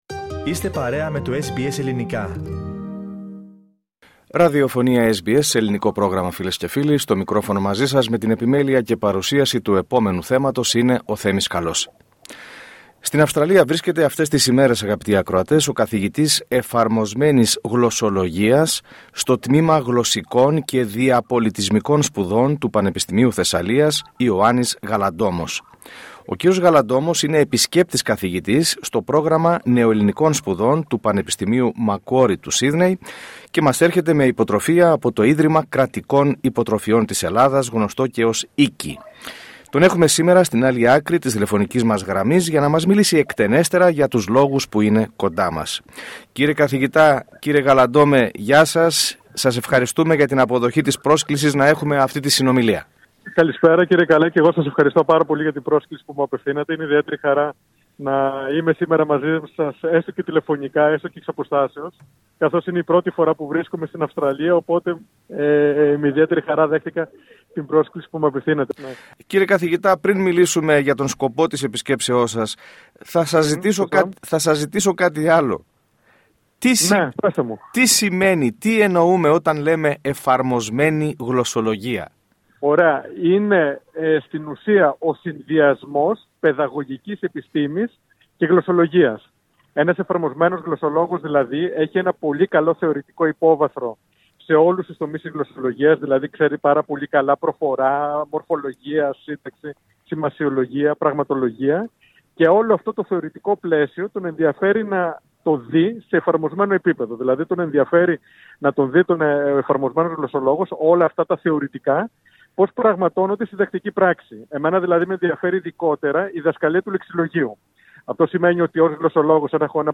Σε συνέντευξη που παραχώρησε στο Πρόγραμμά μας, SBS Greek, εξήγησε αναλυτικά τί σημαίνει ο όρος Εφαρμοσμένη Γλωσσολογία, αναφέρθηκε στην δομή και λειτουργία του Πανεπιστημίου Θεσσαλίας και έδωσε πληροφορίες για το πρόγραμμα του Ιδρύματος Κρατικών Υποτροφιών.